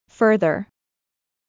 • /ˈfɜːrðər/（アメリカ英語：ファーrザー、少し曖昧な母音）
• 「fur」のような曖昧母音（シュワー）が特徴
• “farther”よりもやや落ち着いたトーン